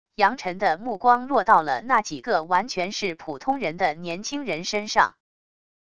杨晨的目光落到了那几个完全是普通人的年轻人身上wav音频生成系统WAV Audio Player